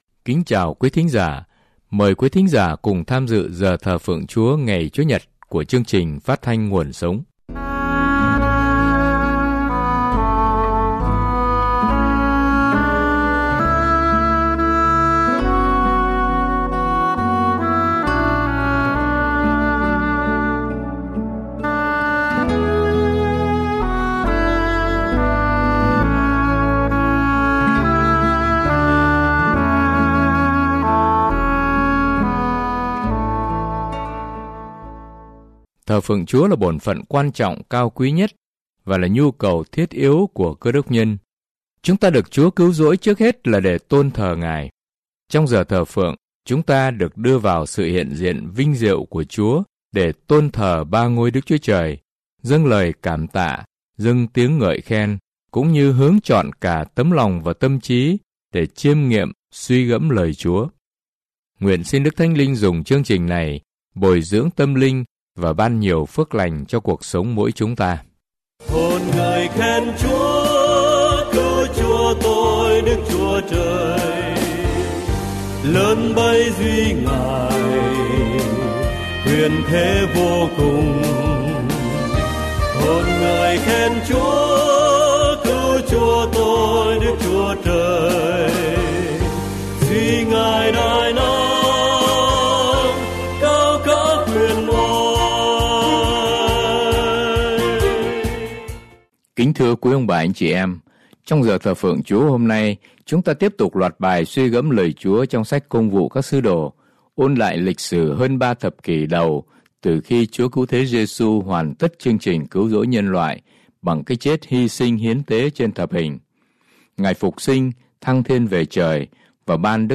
Thờ Phượng Giảng Luận